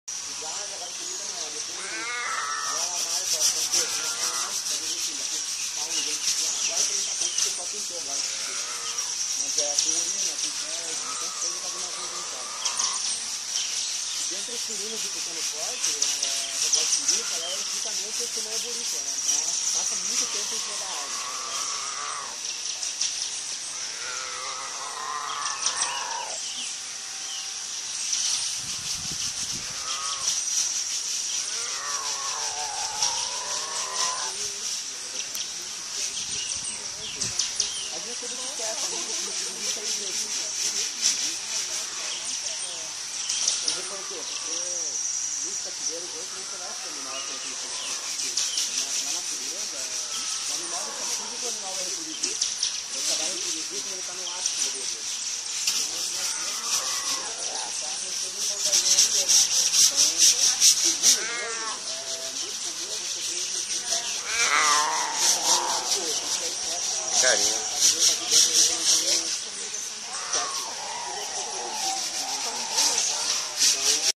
Jaguatirica
Jaguatirica (Leopardus pardalis) de Linnaeus, 1758.
Jaquatirica.mp3